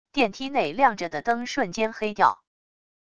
电梯内亮着的灯瞬间黑掉wav音频